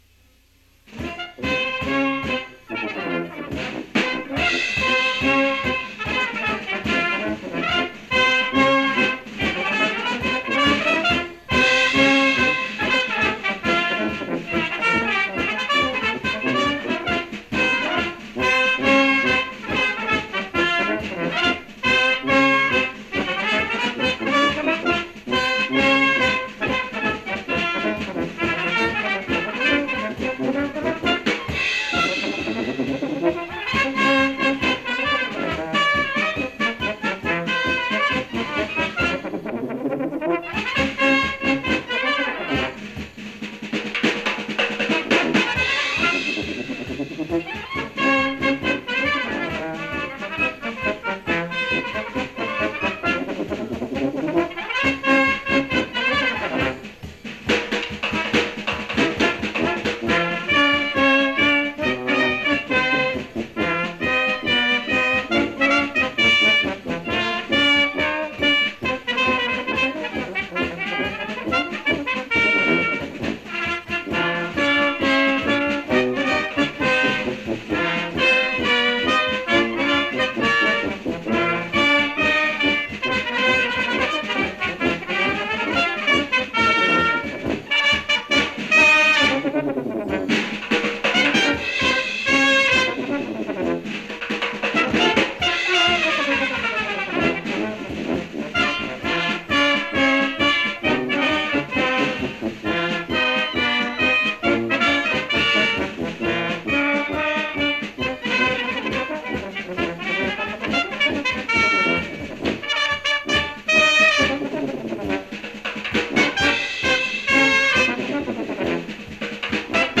Now you can listen to any or all of the playlist from that first WJU Convention in Key Biscayne, Florida in January,1973.
Ragged Rozey novelty (drums) – King (73 KB)